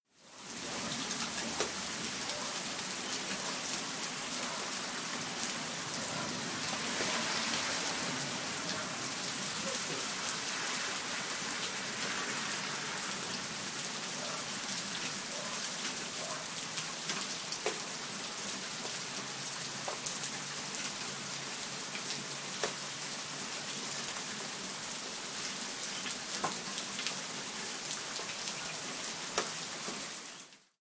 描述：雨下雨淋浴倾盆大雨雨滴滴水湿天气水户外
Tag: 雨滴 天气 外面 淋浴 湿 液滴 暴雨 户外 下雨